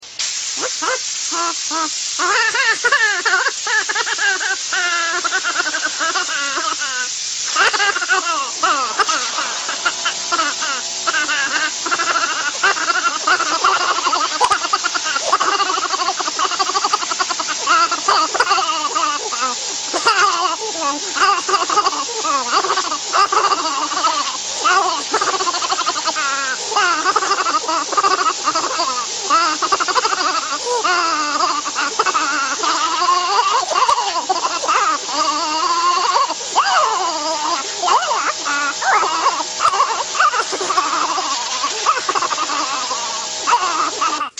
Другие рингтоны по запросу: | Теги: гелий, лай, Собака
Категория: Смешные реалтоны